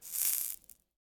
fake_fire_extinguish.3.ogg